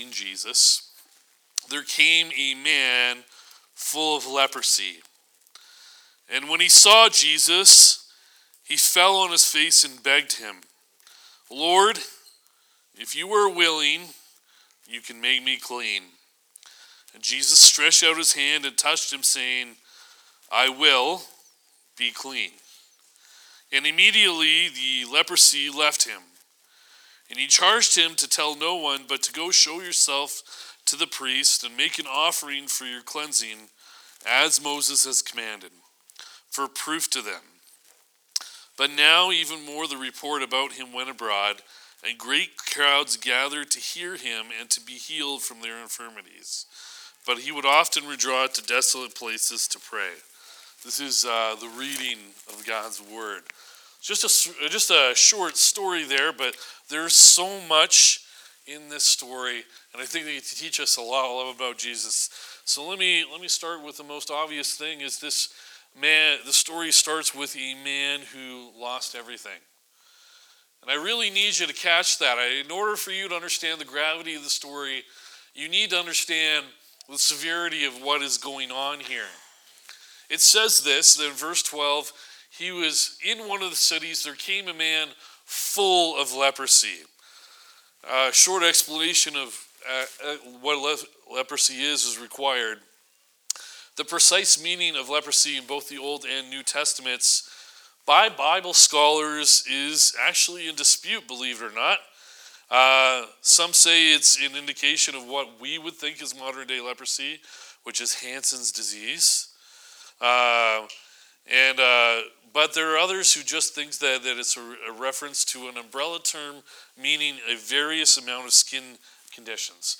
March 1 Sermon